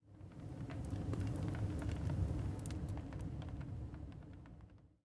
blastfurnace1.ogg